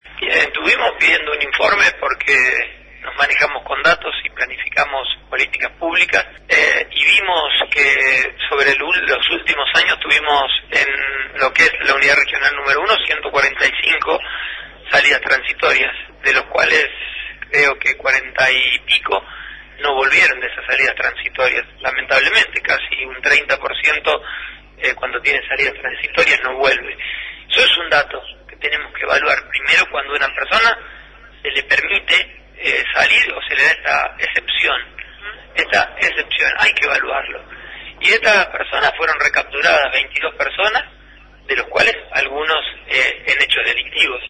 En diálogo con LT10, Maximiliano Pullaro, ministro de Seguridad, expresó que “en los últimos años, en la Unidad Regional Nº I tuvimos 145 personas con salidas transitorias de los cuales más de 40 no volvieron.